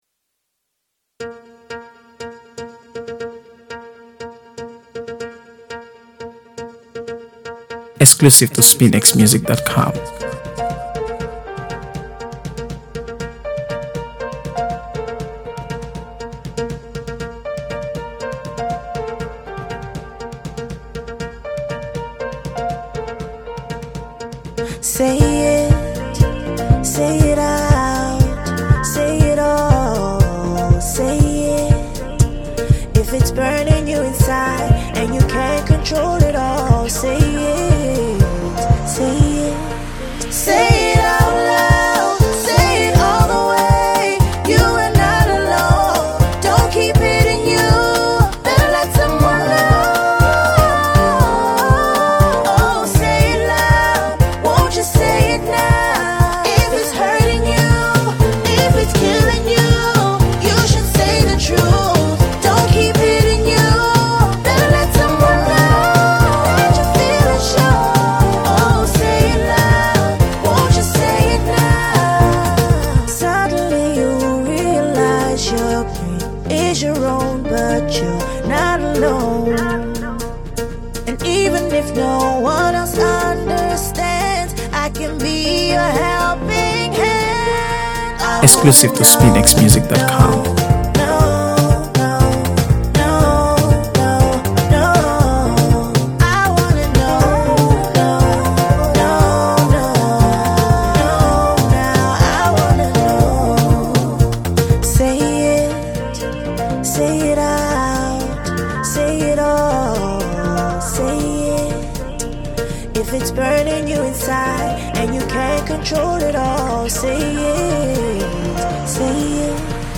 AfroBeats | AfroBeats songs
With its infectious beat and smooth vocals